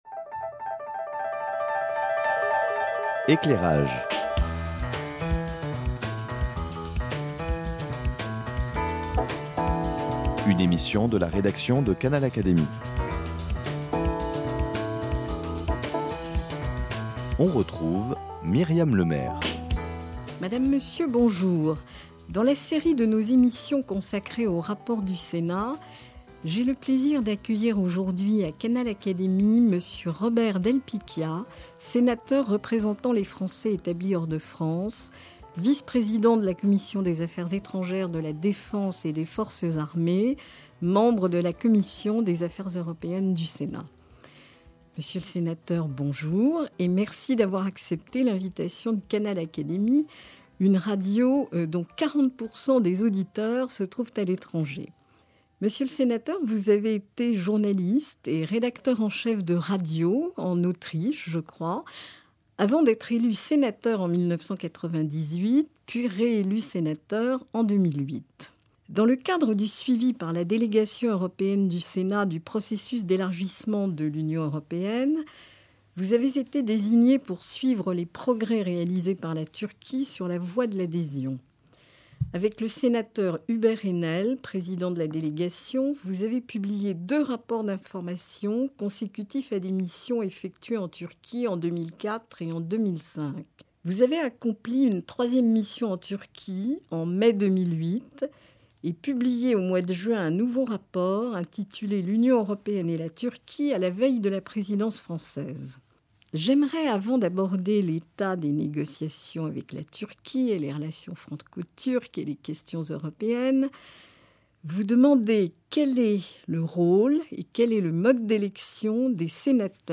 Au début de cette émission, Robert del Picchia, Sénateur représentant les Français établis hors de France depuis 1998, rappelle le rôle et le mode d’élection des 12 Sénateurs qui représentent les Français expatriés.